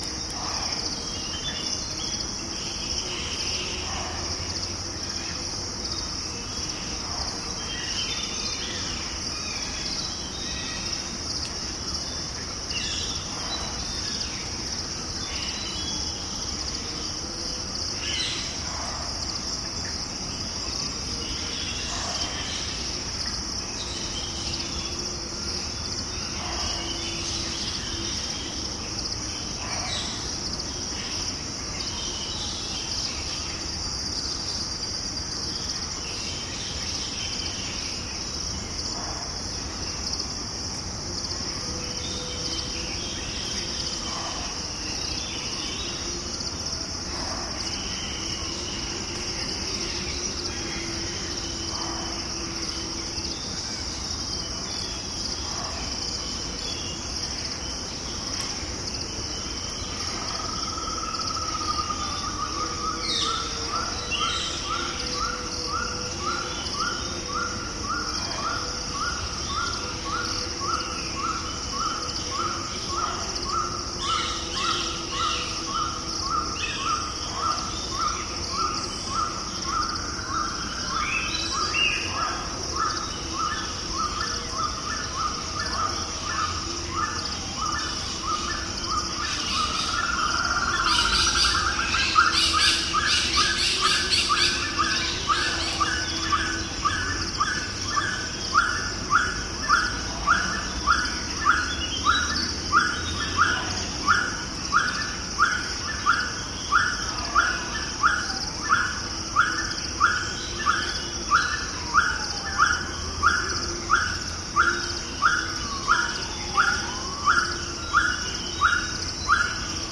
Hantana forest at dusk, Sri Lanka